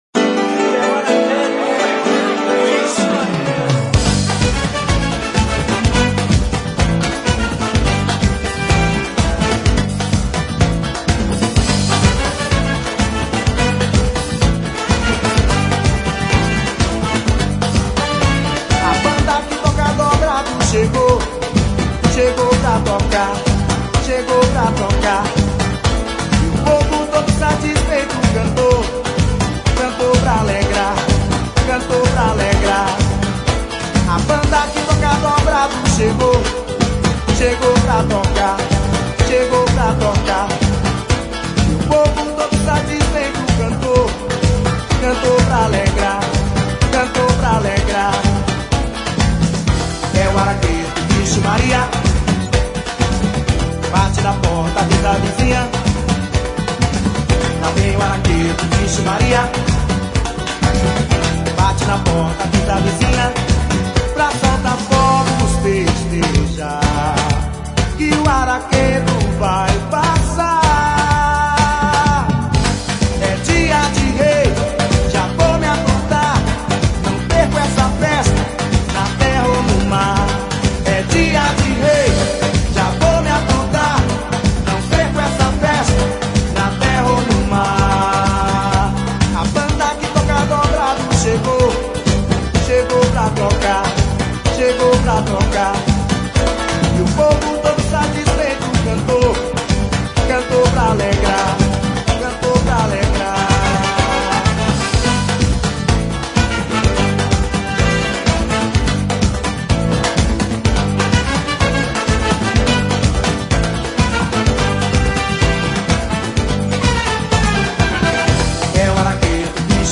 Axe